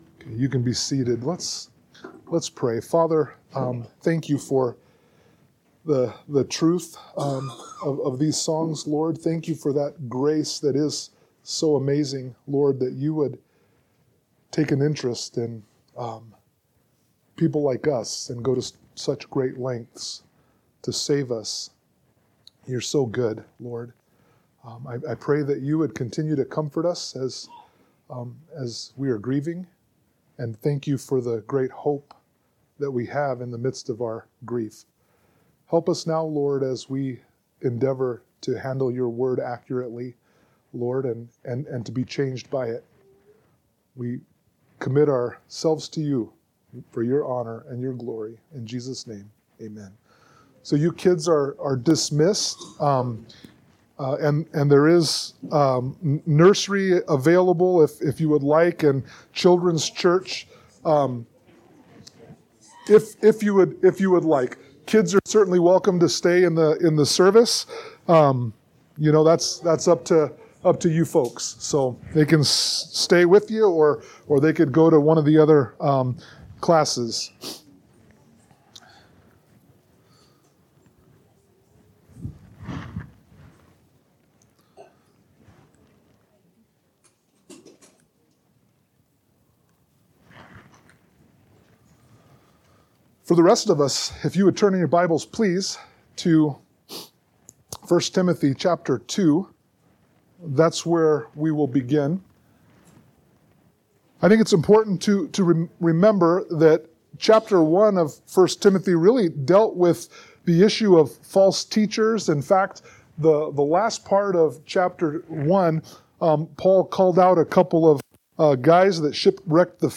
Sermon-8_10_25.mp3